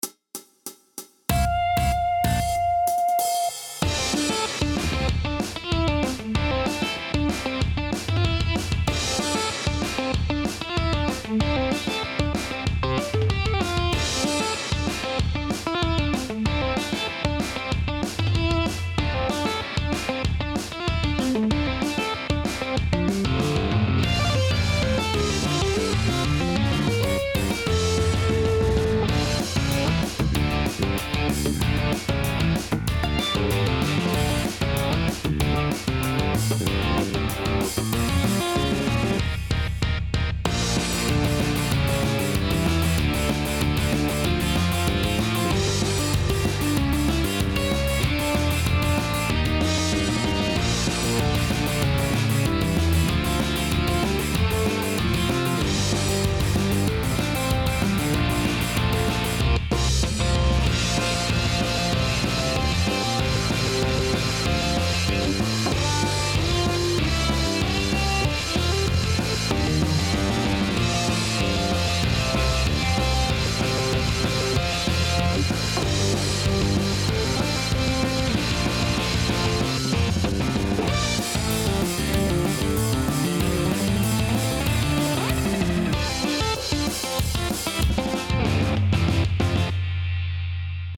绝对的激情，绝对的燃烧，绝对的好听！
谱内音轨：独奏电吉他